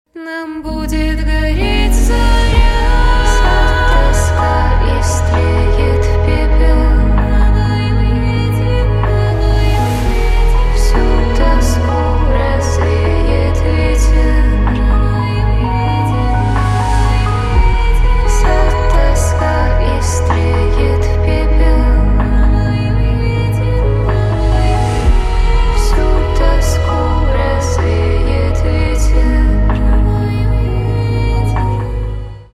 Спокойные И Тихие Рингтоны
Поп Рингтоны